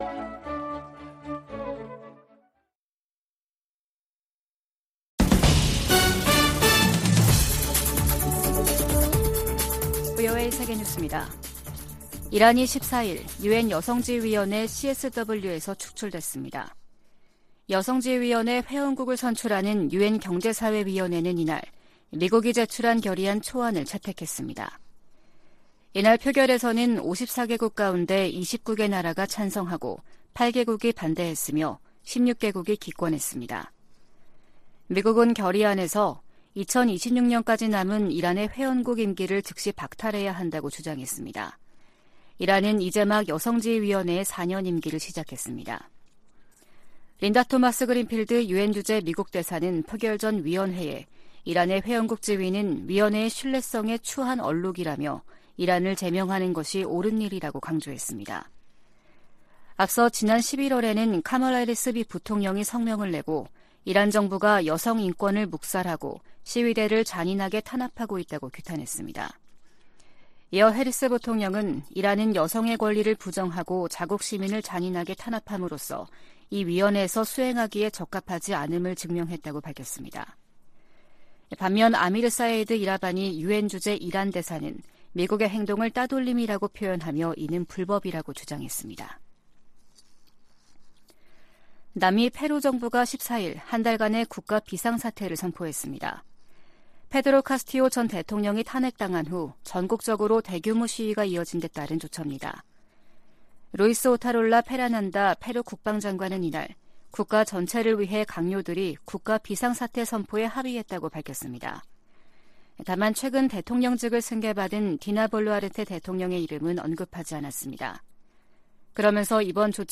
VOA 한국어 아침 뉴스 프로그램 '워싱턴 뉴스 광장' 2022년 12월 15일 방송입니다. 미국 국무부가 유럽연합(EU)의 대북 추가 독자제재 조치를 높이 평가하면서 북한 정권에 책임을 물리기 위해 동맹, 파트너와 협력하고 있다고 밝혔습니다. 북한의 인도주의 위기는 국제사회의 제재 때문이 아니라 김정은 정권의 잘못된 정책에서 비롯됐다고 유엔 안보리 대북제재위원장이 지적했습니다.